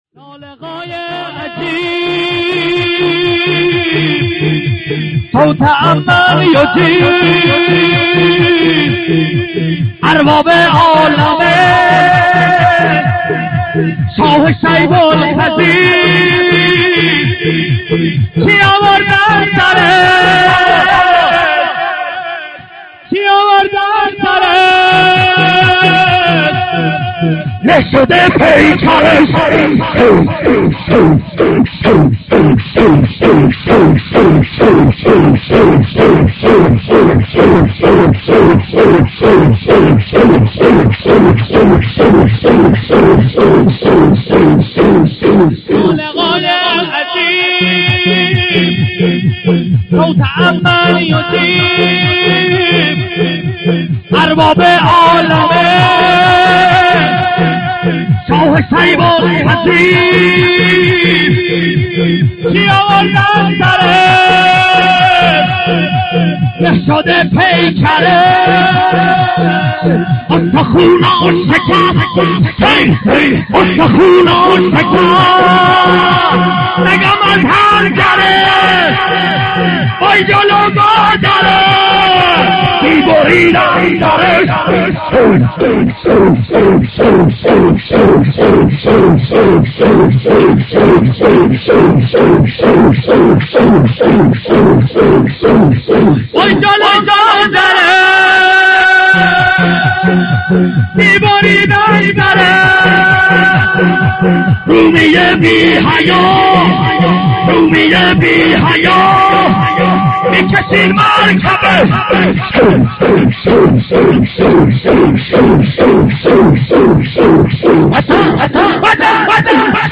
شب شهادت امام صادق(ع) ایام صادقیه(شب دوم) 94/05/19
شور